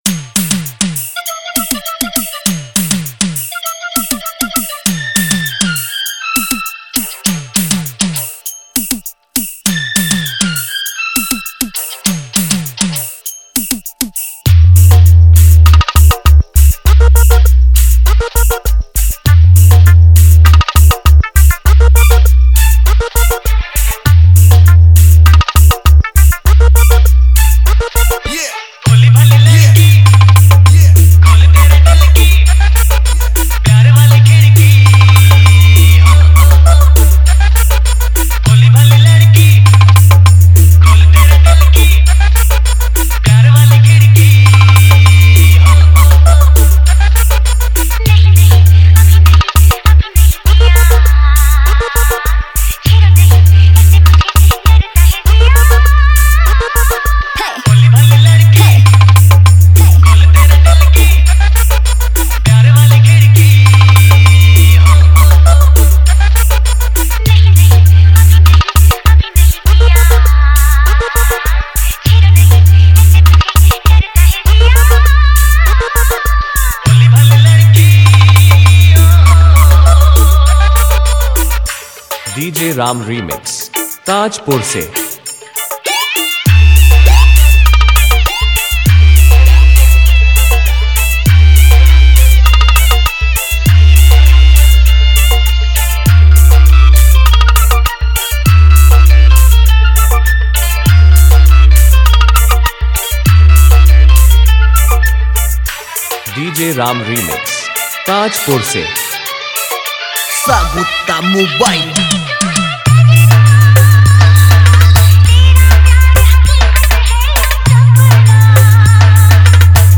Humming Bass